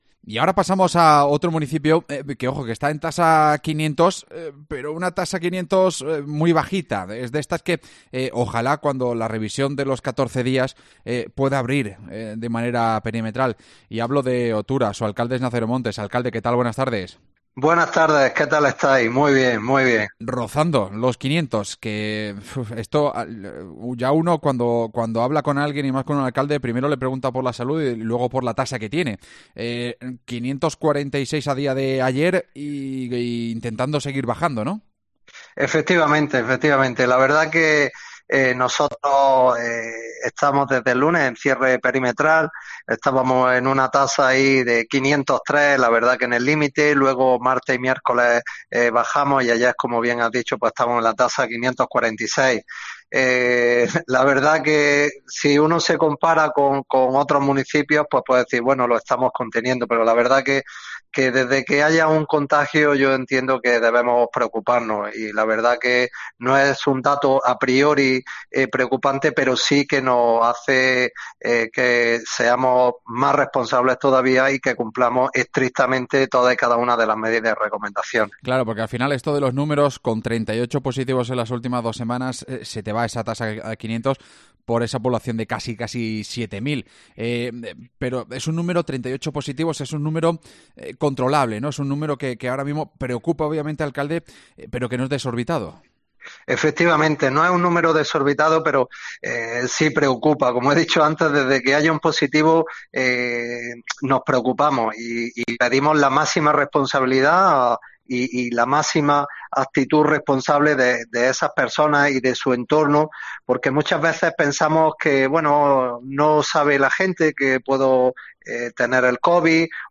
AUDIO: Su alcalde, Nazario Montes, ha estado hoy en COPE Granada